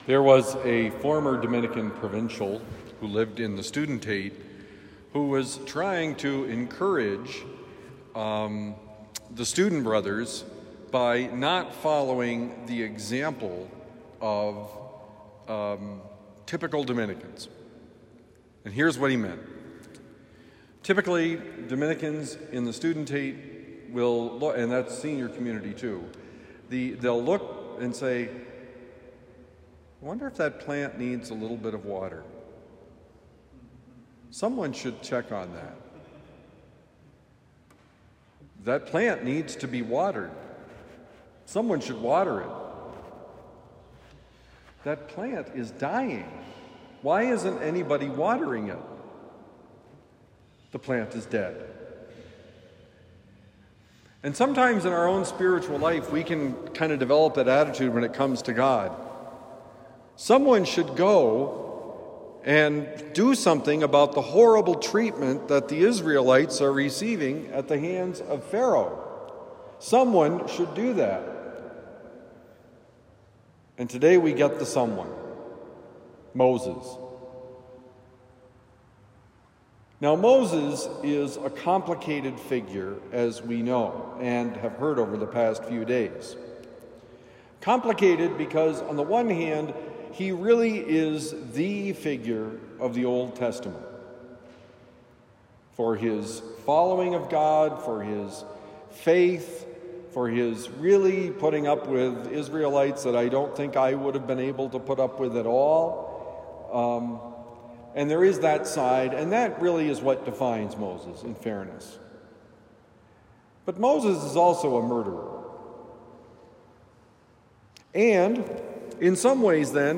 God wants you: Homily for Thursday, July 17, 2025